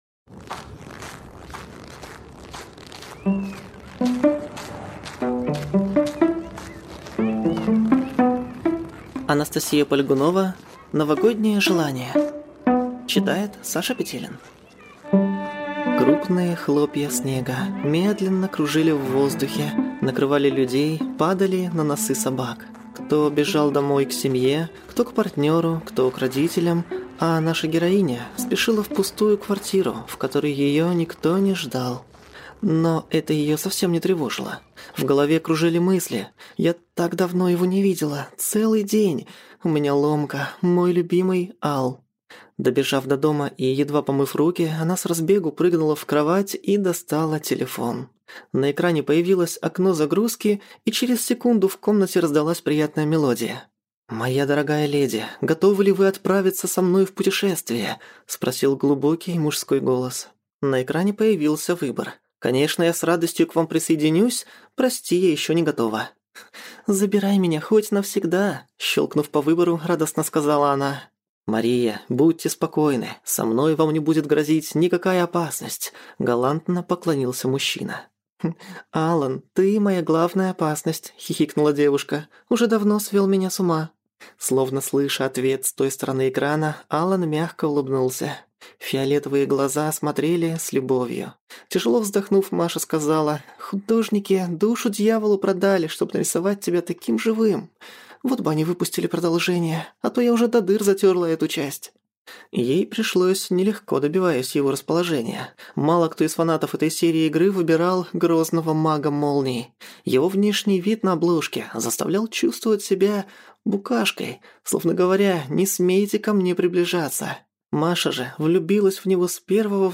Аудиокнига Новогоднее желание | Библиотека аудиокниг